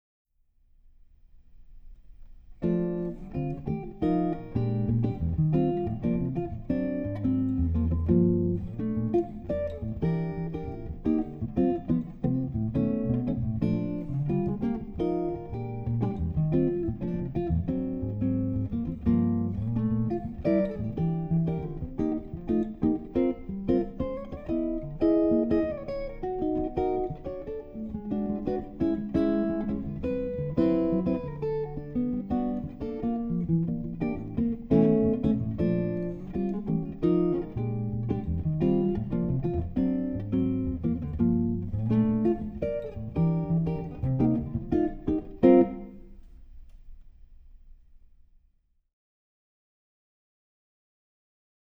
Solo Jazz Guitar
recorded with a D'Angelico NYL-II